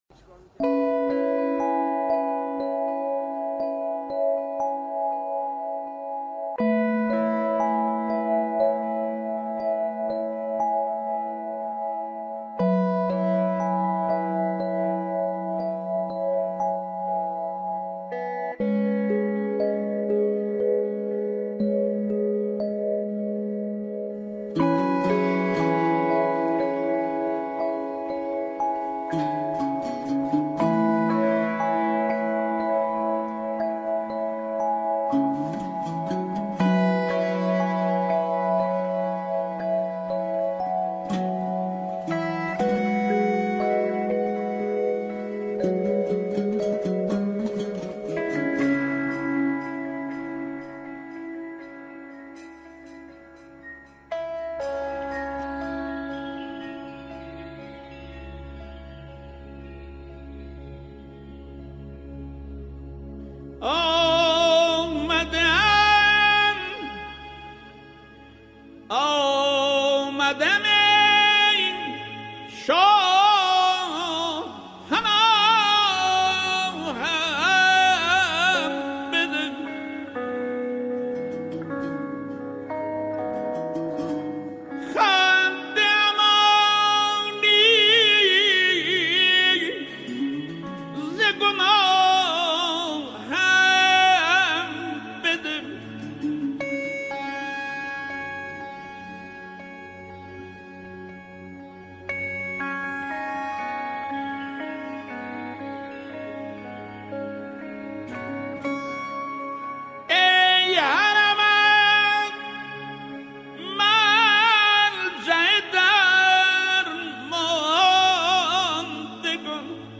این شاعر در گفت‌وگویی  به ماجرای سروده شدن این شعر اشاره کرده بود :